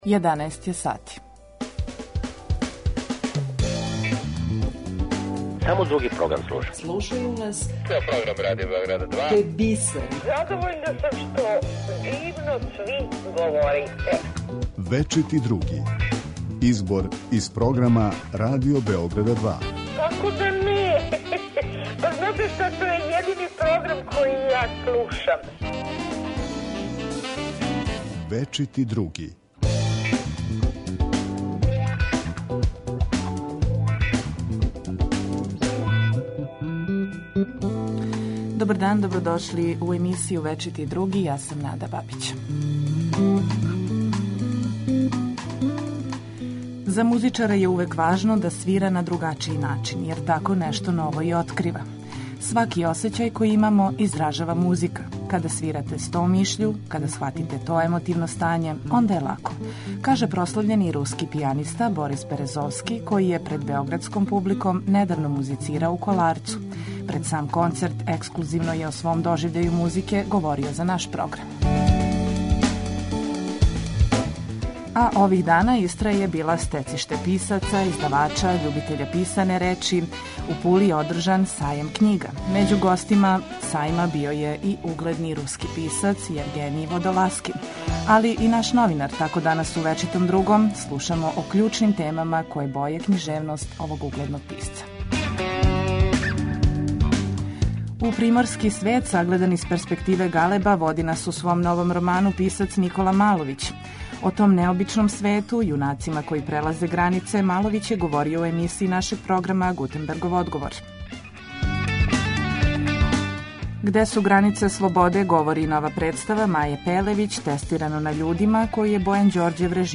У данашњој емисији Вечити Други чућемо славног руског пијанисту Бориса Березовског, који је за наш програм говорио о свом доживљају музике.